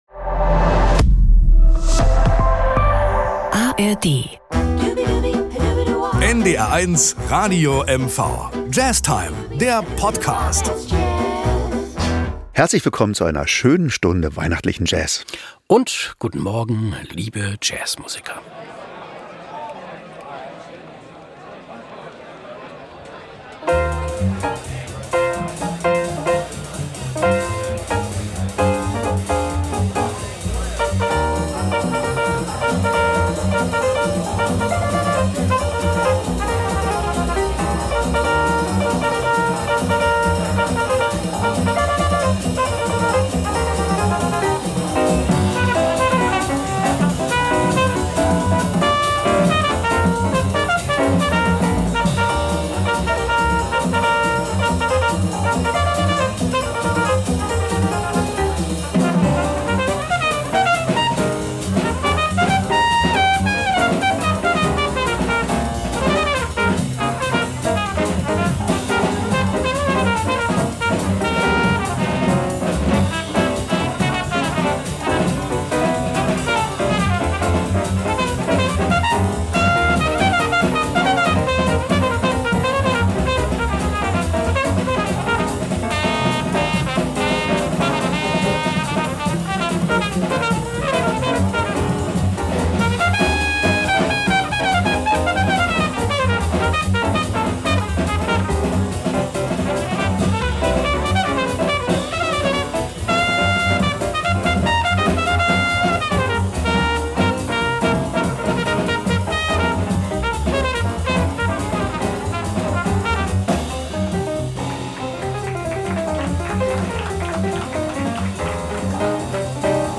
mit spannenden und besinnlichen Versionen